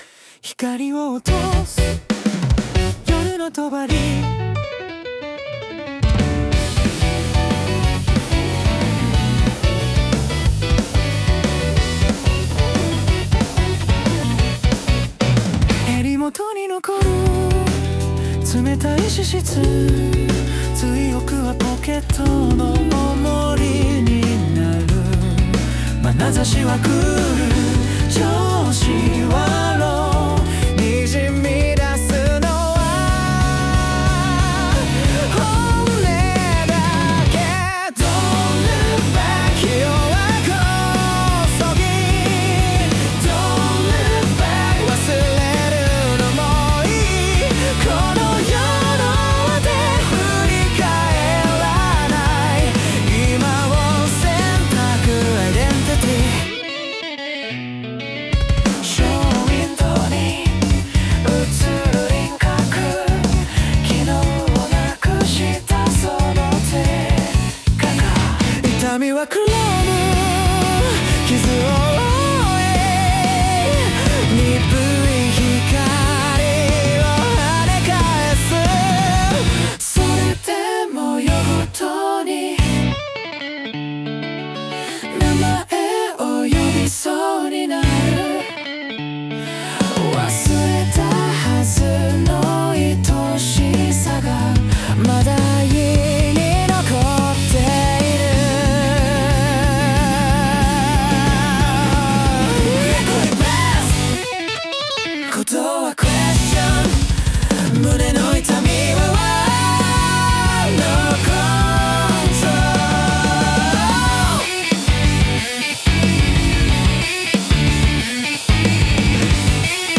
男性ボーカル
イメージ：J-POP,男性ボーカル,シティーPOP,おしゃれ,クール,かっこいい,大人